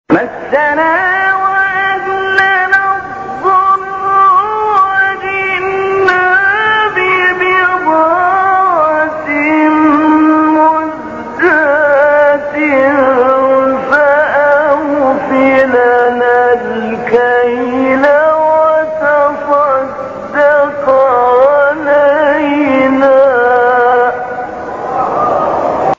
به گزارش خبرگزاری بین المللی قرآن(ایکنا)، شش فراز صوتی با صوت محمود علی البناء، قاری برجسته مصری در کانال تلگرامی قاریان مصری منتشر شده است.
این مقاطع صوتی از تلاوت سوره یوسف بوده است که در مقام‌های بیات، صبا، نهاوند، رست و سه گاه اجرا شده‌اند.
ترکیب مقام صبا و عجم